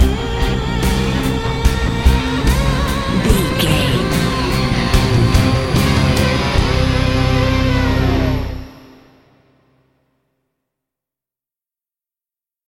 In-crescendo
Thriller
Aeolian/Minor
synthesiser